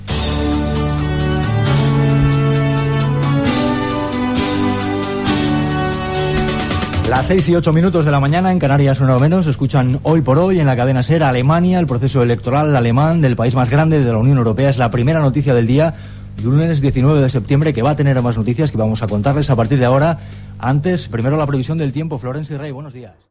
Primera edició del programa presentada per Carlos Francino.
Sintonia del programa, hora, eleccions alemanyes, el temps
Info-entreteniment